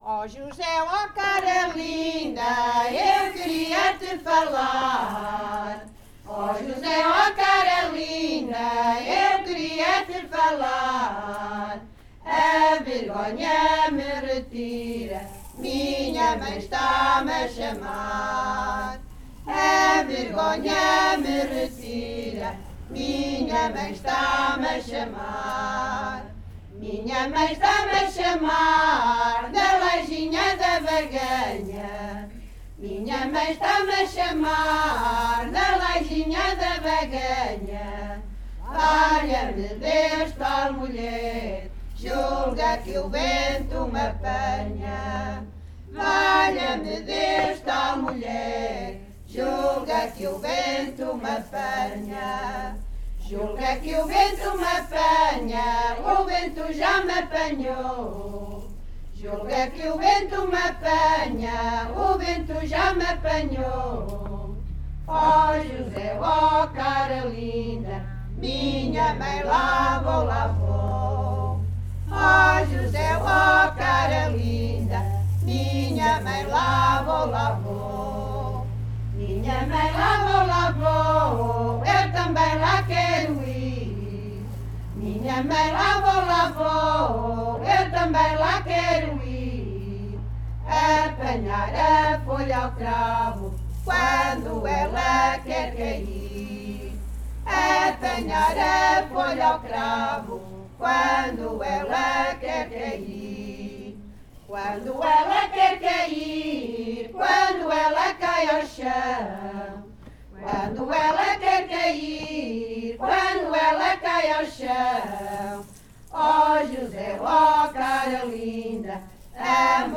Grupo Etnográfico de Trajes e Cantares do Linho de Várzea de Calde durante o encontro SoCCos em Portugal - O ripar do linho (Versão 1).